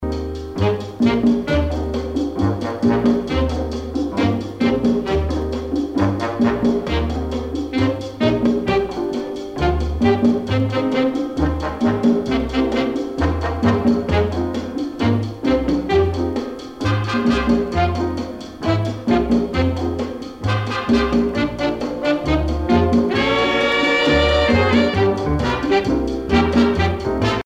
danse : cha cha cha
Pièce musicale éditée